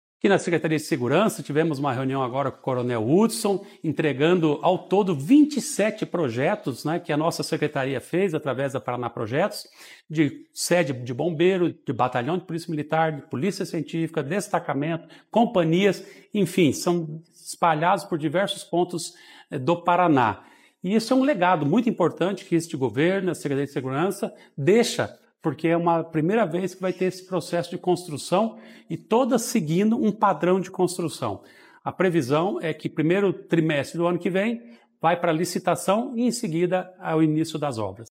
Sonora do secretário do Planejamento, Ulisses Maia, sobre o desenvolvimento de projetos para novas bases das forças de segurança